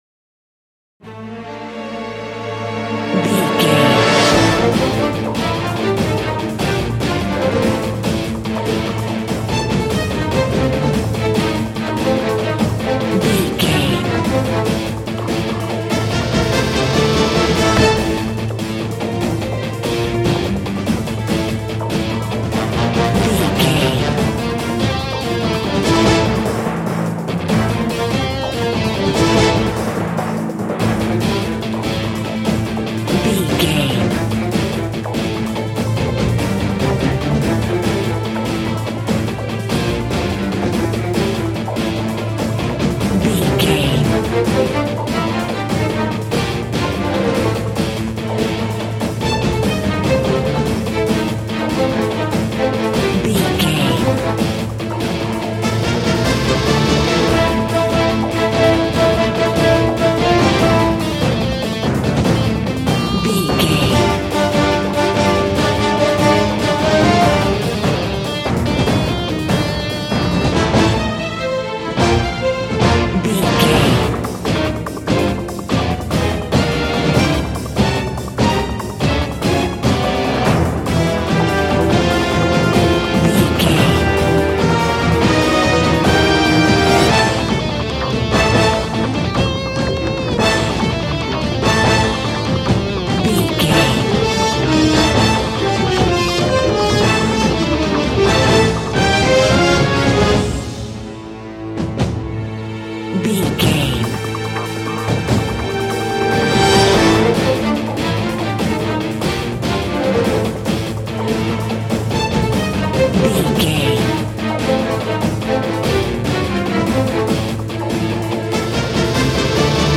Phrygian
angry
futuristic
aggressive
orchestra
percussion
synthesiser
dark
mechanical